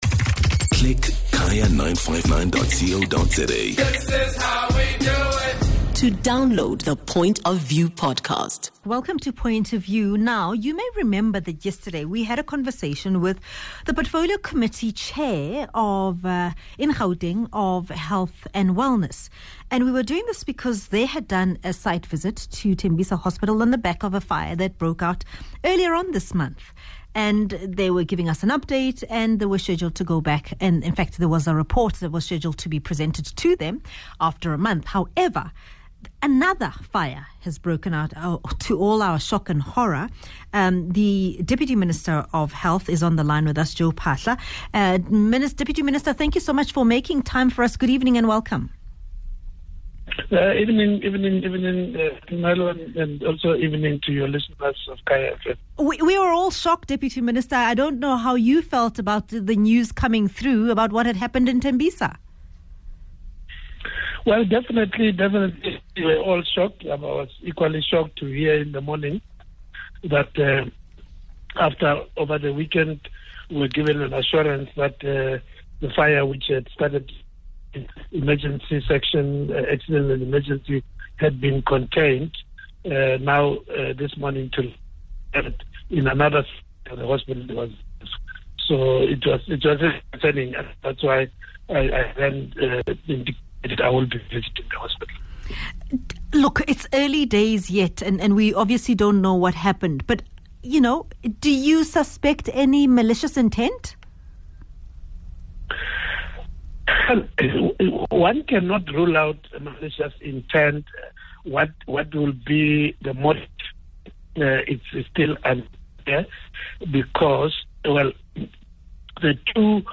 speaks to the Minister Phaahla